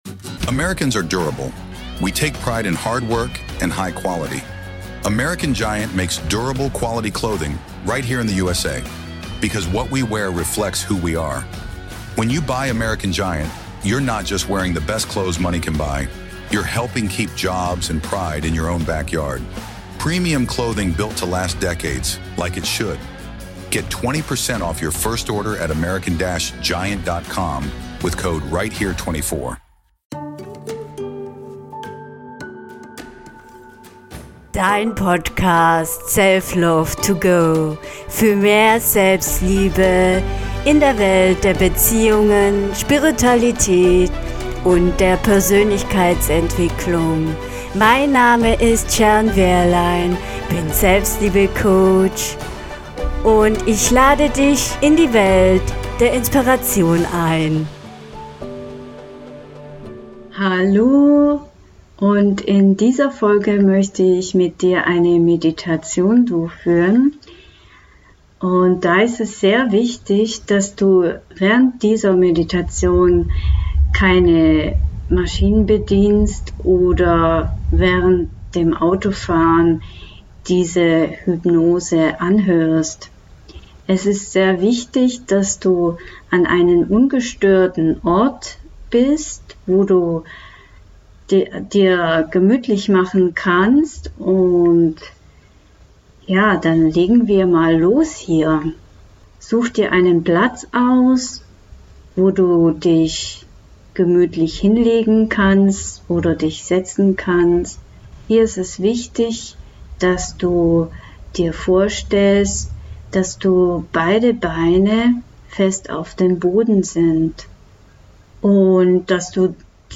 In dieser Folge wirst du durch eine Meditation durchgeführt. Hier geht es darum, deine zukünftige Version mit dir zu verbinden, um deine Selbstliebe zu stärken.
Selbstliebe-staerken_Meditation.mp3